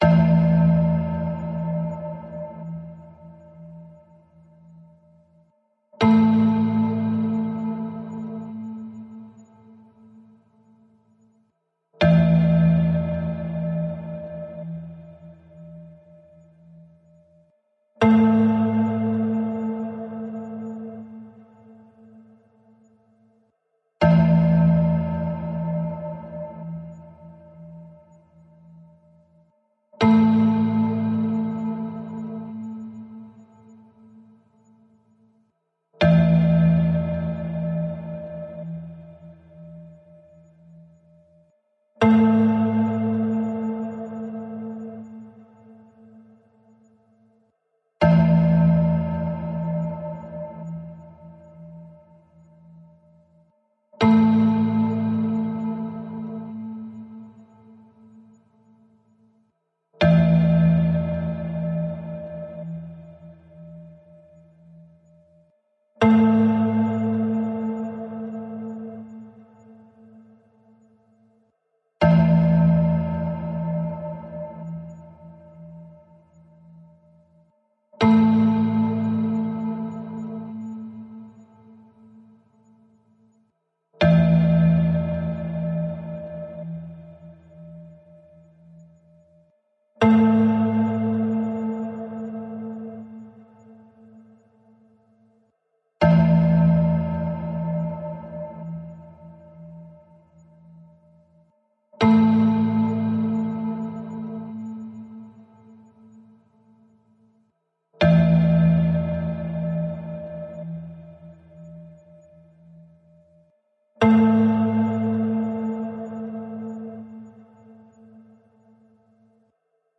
singing-bowl.mp3